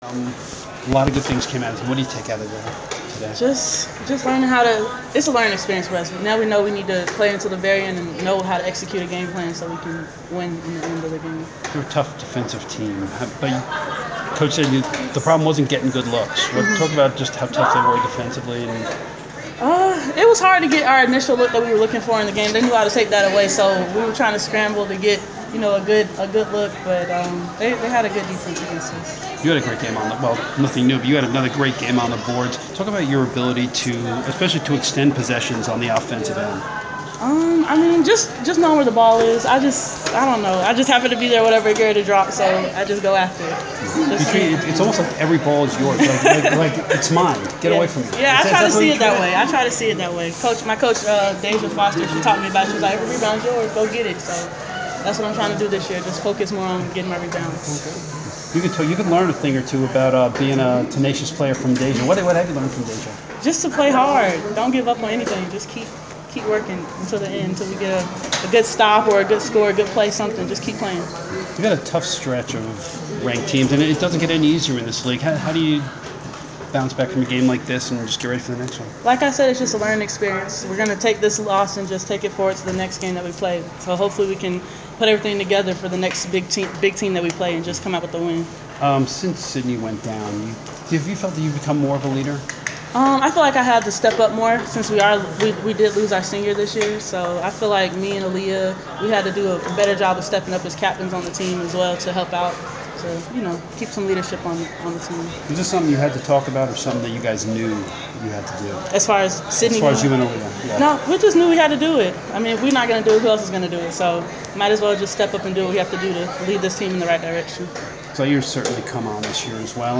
Inside the Inquirer: Postgame interview